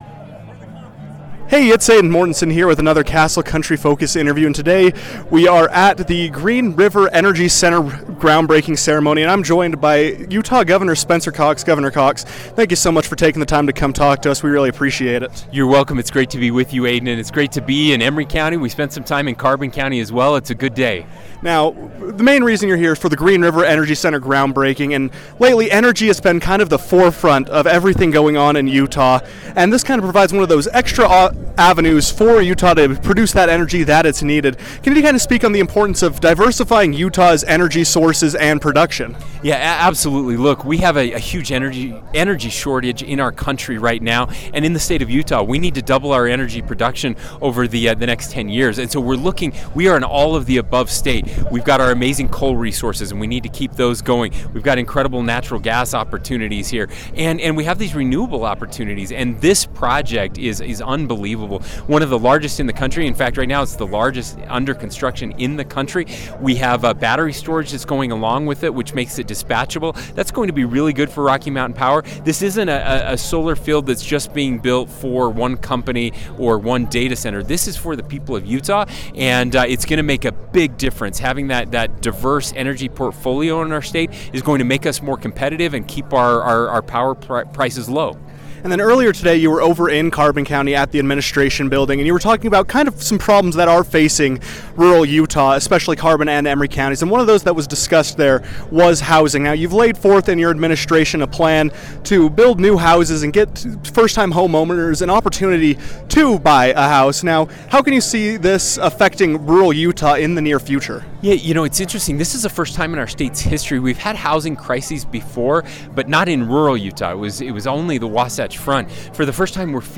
Hearing these concerns, Castle Country Radio spoke with Utah Gov. Spencer Cox to gain insight into what problems he sees in rural areas and what the state is doing to fix them.